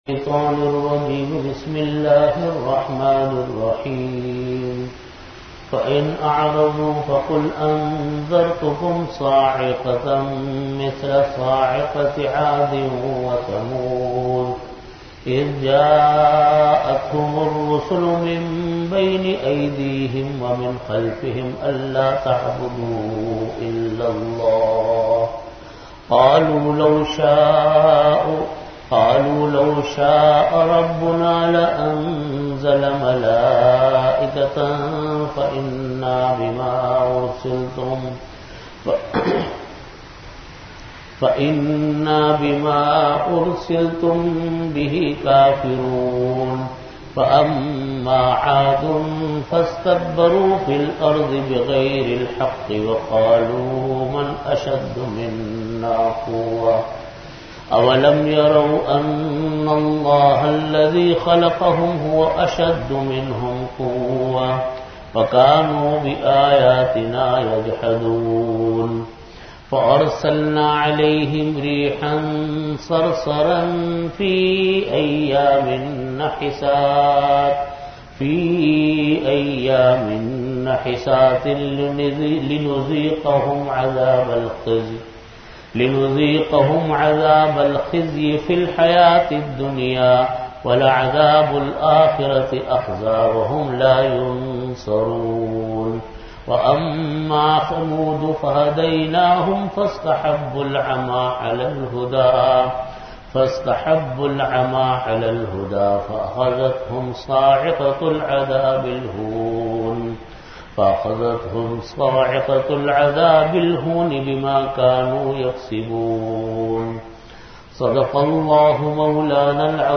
Originally delivered in After Asar Prayer at Jamia Masjid Bait-ul-Mukkaram, Karachi.